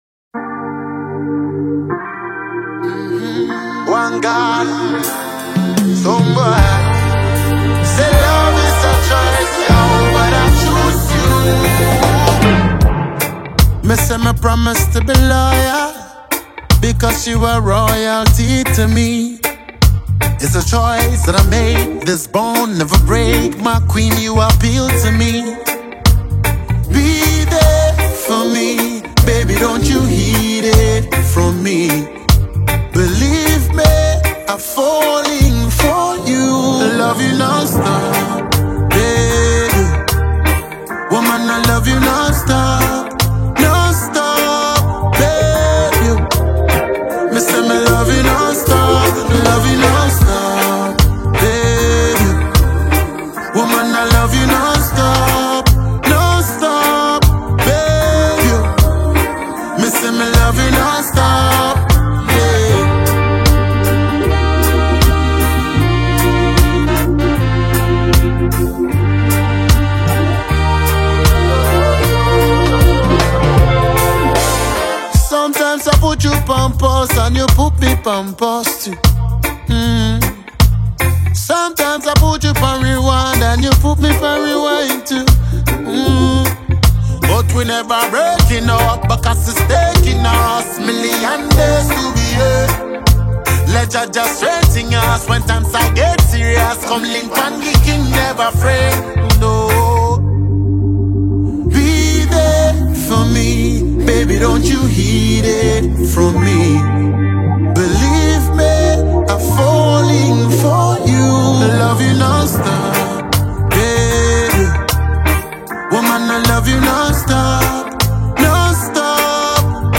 Afropop song